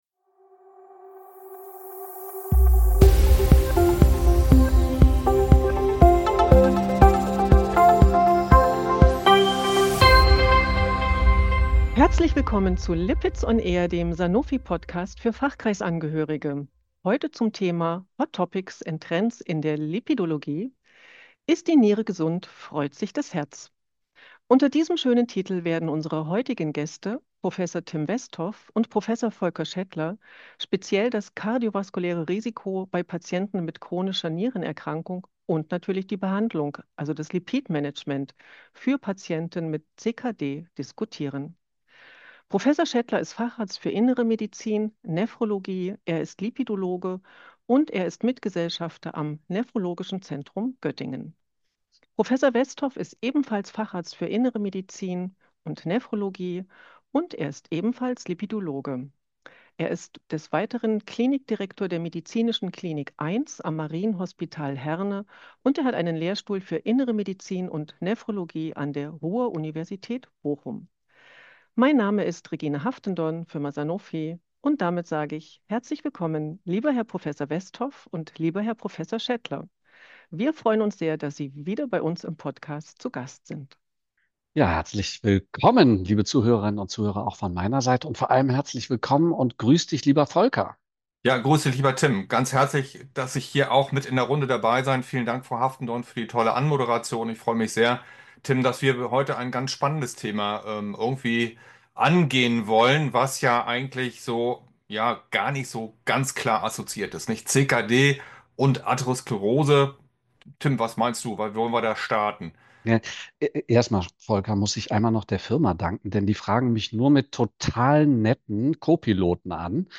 in einer kritischen Diskussion zum kardiovaskulären Risiko bei chronischer Nierenerkrankung und deren Behandlung.